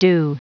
Prononciation du mot dew en anglais (fichier audio)
Prononciation du mot : dew